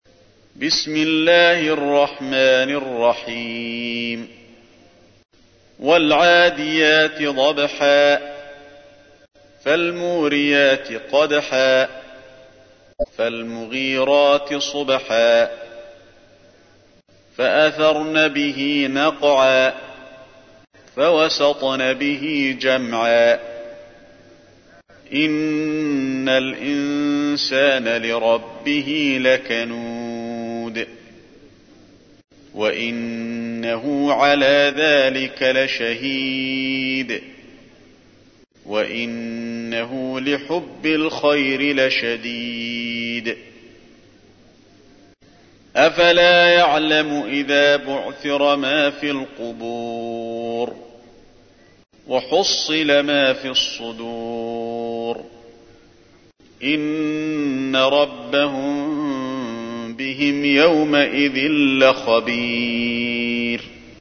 تحميل : 100. سورة العاديات / القارئ علي الحذيفي / القرآن الكريم / موقع يا حسين